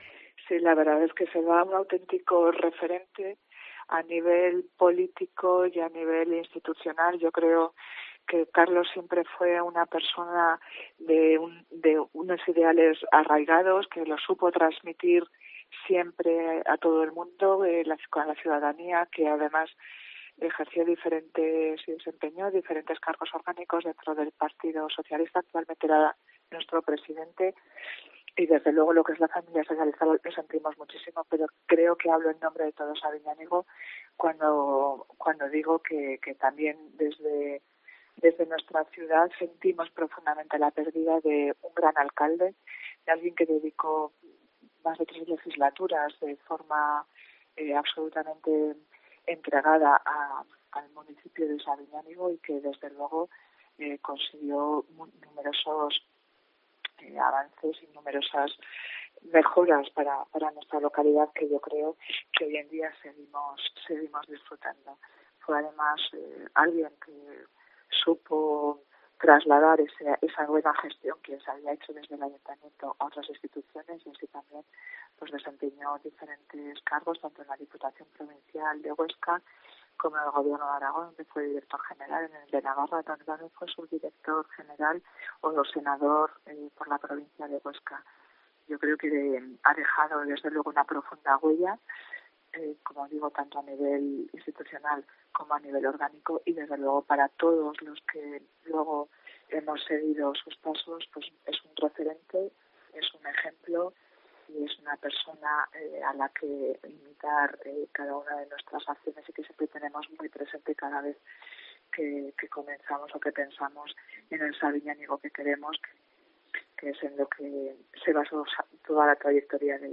Esucha a la alcaldesa, Berta Fernández en COPE.